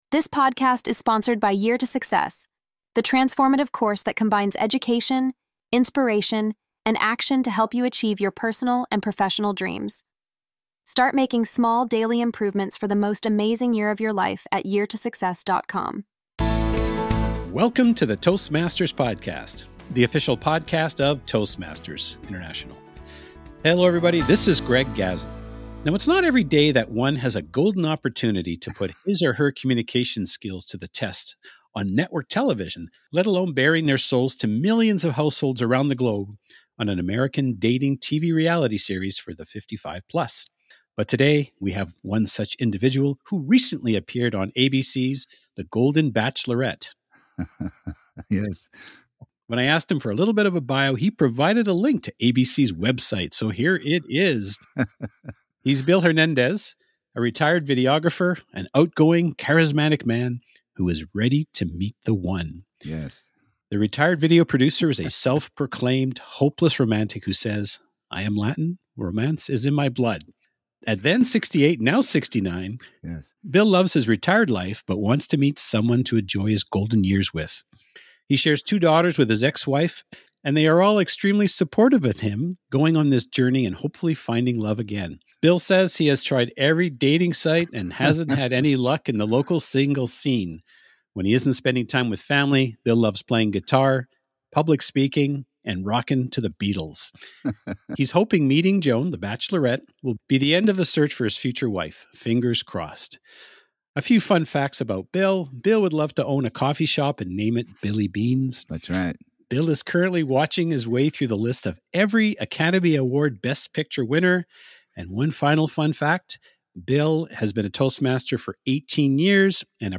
The Toastmasters Podcast is a talkshow designed to amplify the Toastmaster magazine content, featuring interviews with Toastmasters, contributors and those connected with the articles of this monthly publication.